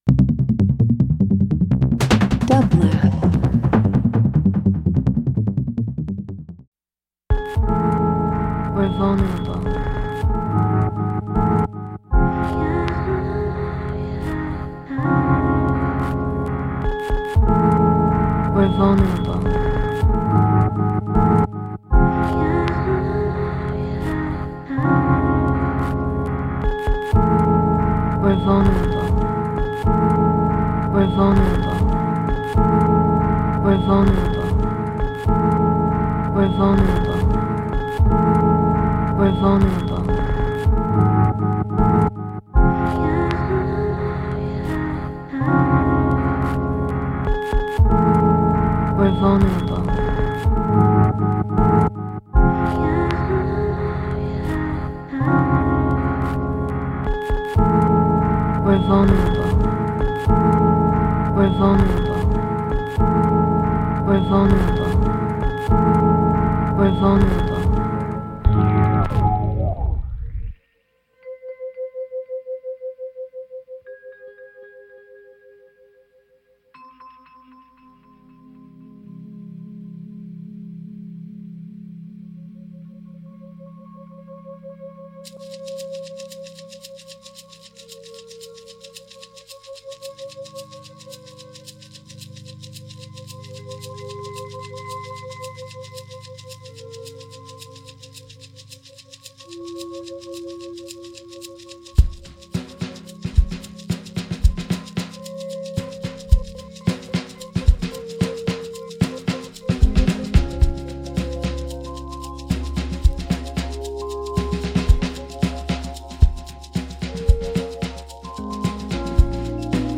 Beats Disco House International